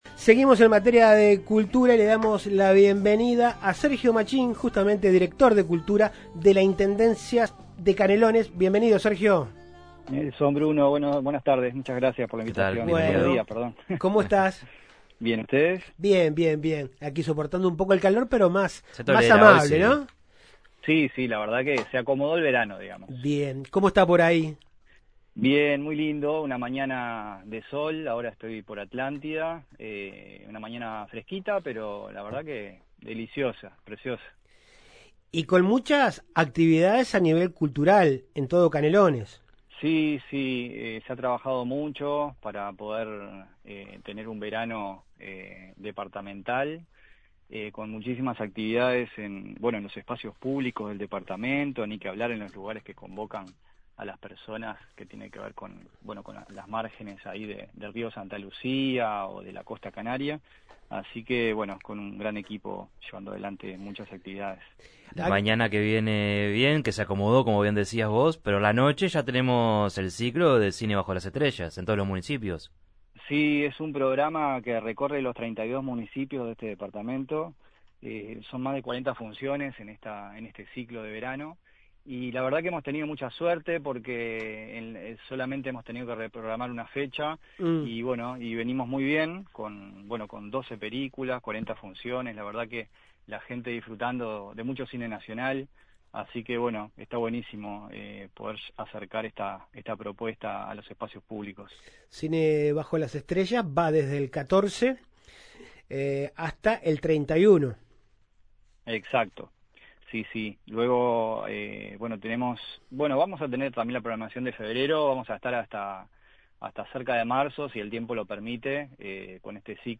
Entrevista a Sergio Machín, director de Cultura de la Intendencia de Canelones
ENTREVISTA-SERGIO-MACHIN.mp3